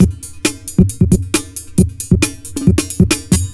ELECTRO 14-L.wav